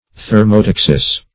Search Result for " thermotaxis" : The Collaborative International Dictionary of English v.0.48: Thermotaxis \Ther`mo*tax"is\, n. [NL.; thermo- + Gr.